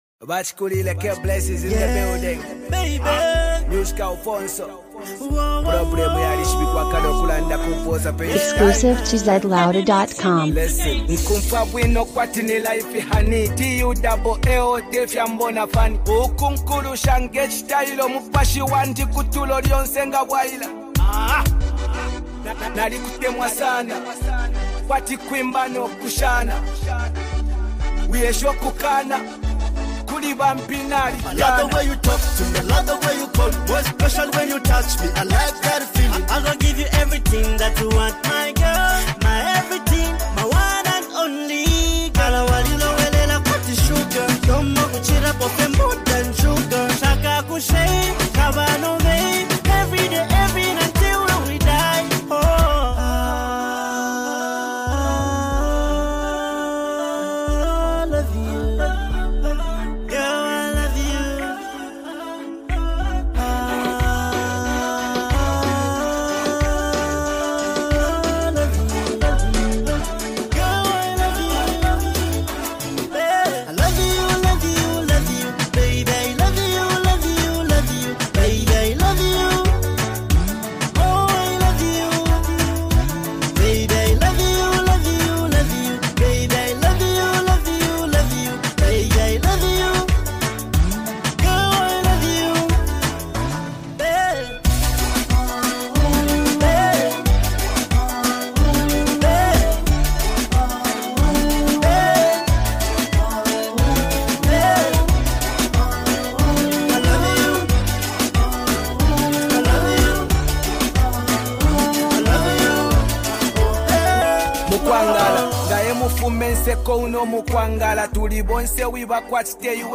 Rapper
Heart Touching Vibe